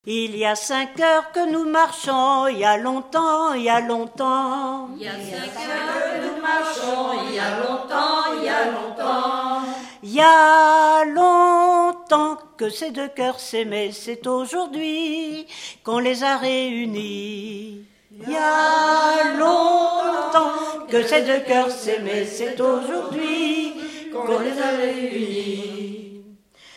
Mémoires et Patrimoines vivants - RaddO est une base de données d'archives iconographiques et sonores.
Genre énumérative
Pièce musicale inédite